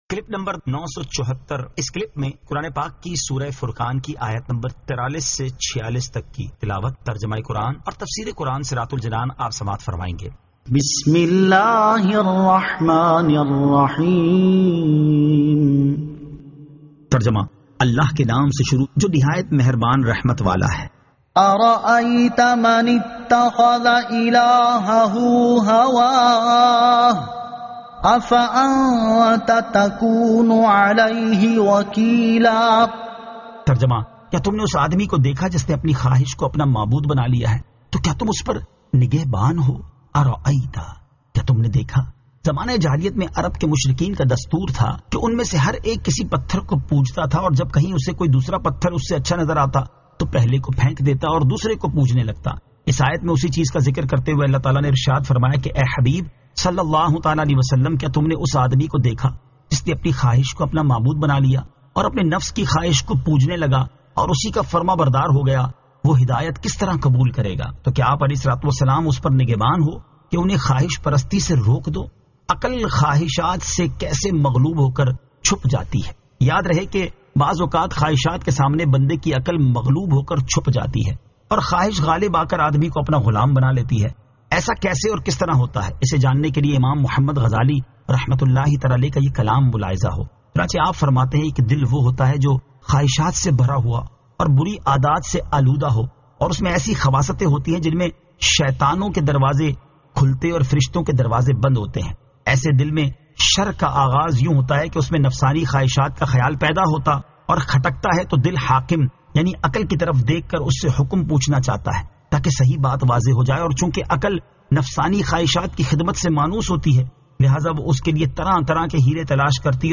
Surah Al-Furqan 43 To 46 Tilawat , Tarjama , Tafseer